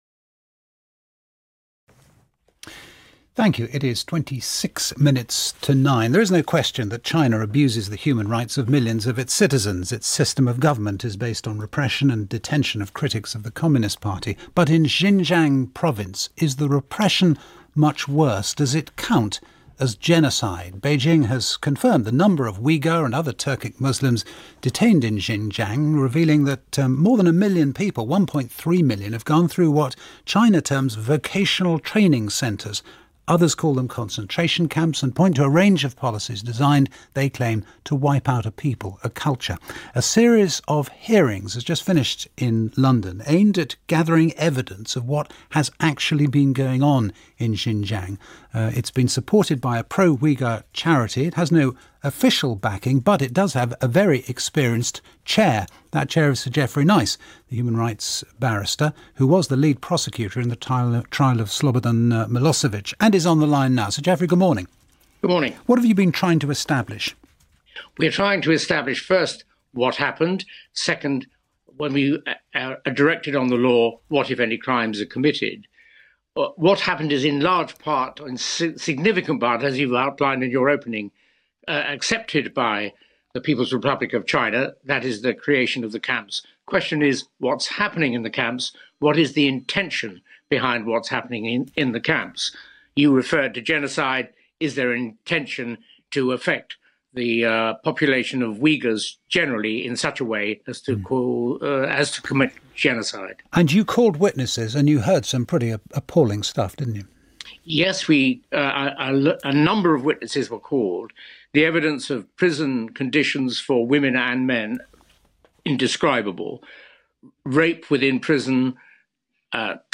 Sir Geoffrey Nice BBC Interview (audio) - Uyghur Tribunal
Sir Geoffrey Nice QC speaks to the BBC about the ‘Uyghur Tribunal’.[/vc_column_text][/vc_column][/vc_row]